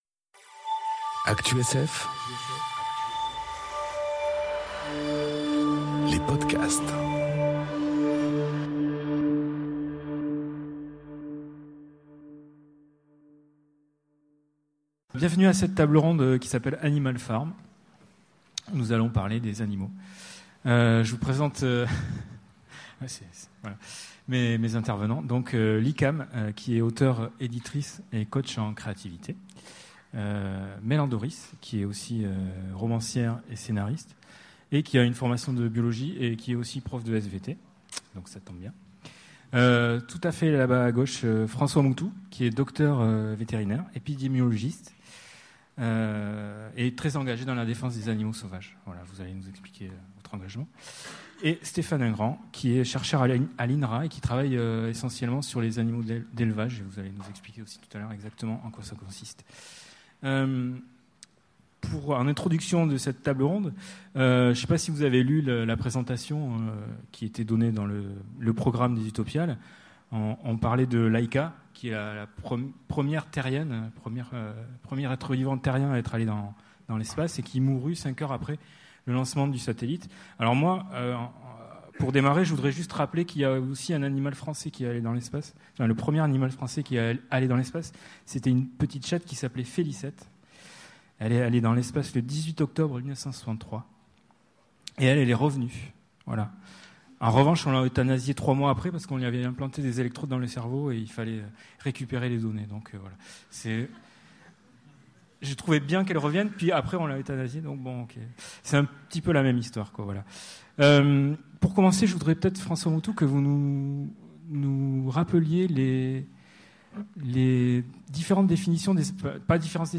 Conférence Animal Farm enregistrée aux Utopiales 2018